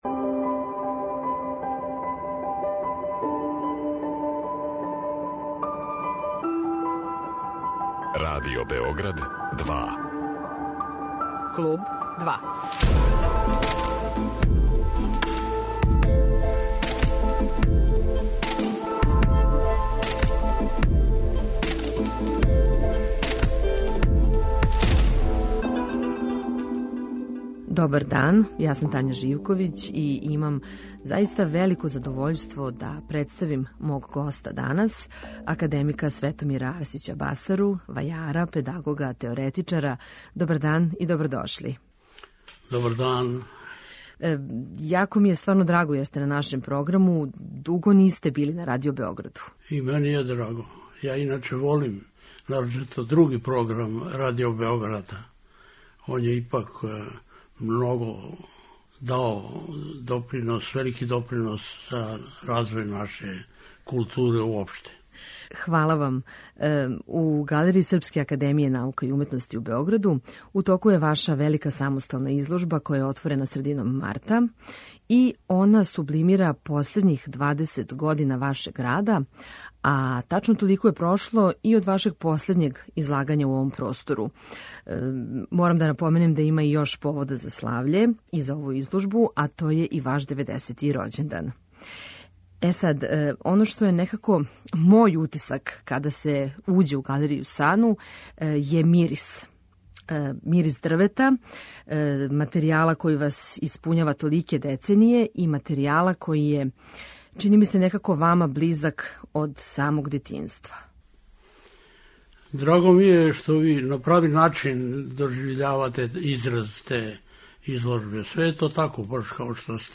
Гост 'Клуба 2' је академик Светомир Арсић Басара, вајар, педагог и ликовни теоретичар.